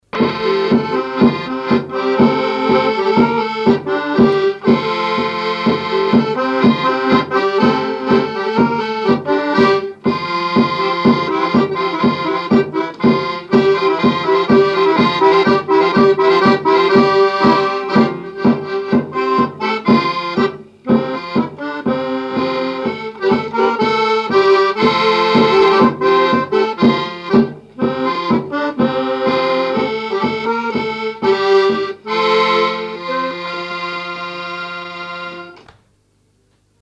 danse : ronde : grand'danse
répertoire à l'accordéon chromatique et grosse caisse
Pièce musicale inédite